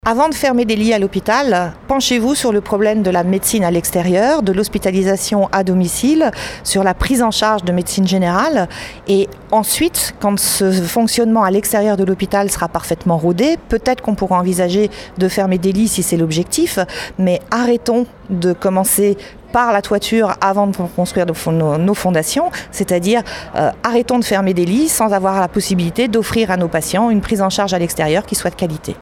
infirmière, qui a également un message à adresser à la ministre :